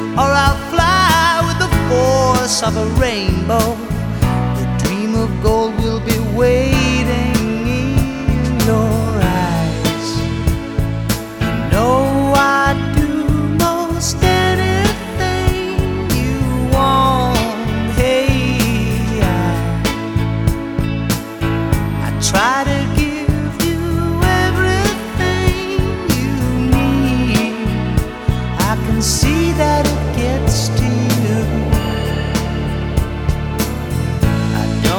Жанр: Поп музыка / Рок / R&B / Танцевальные / Соул